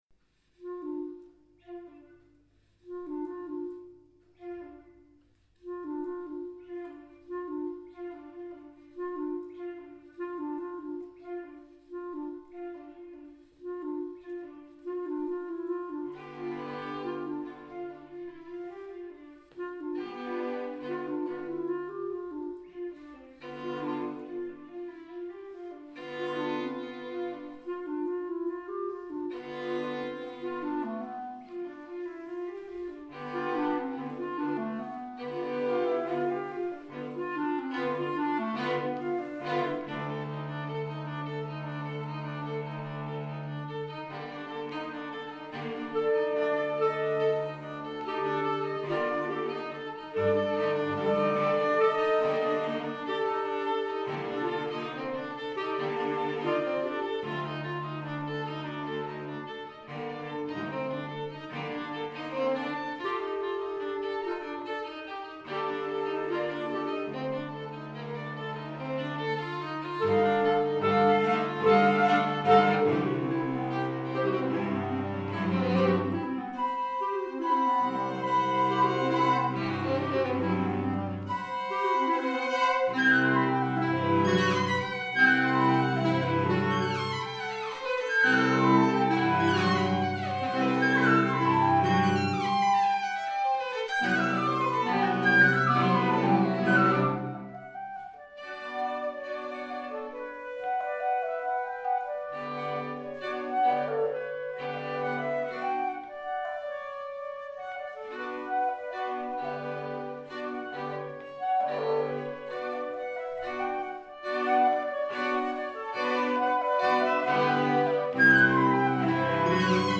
Alt Rock for chamber quartet